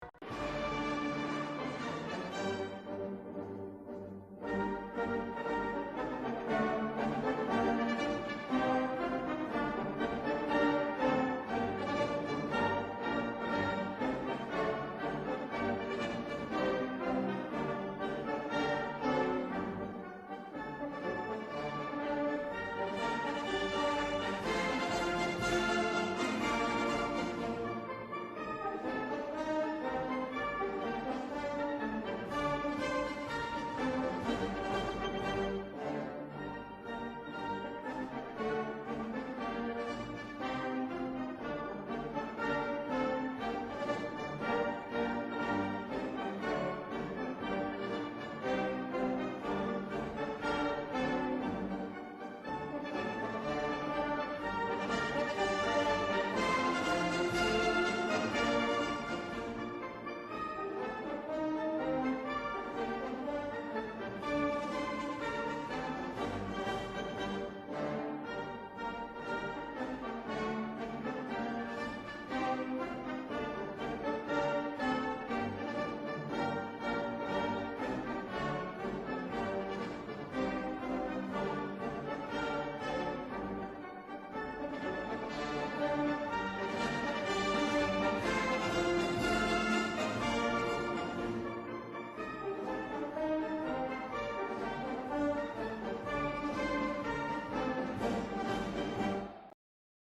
Datei Dateiversionen Dateiverwendung Bratins_ud_Sejas_instrumental.mp3  (Dateigröße: 3,83 MB, MIME-Typ: audio/mpeg ) Beschreibung Die Nationalhymne der VRA.
Bratins_ud_Sejas_instrumental.mp3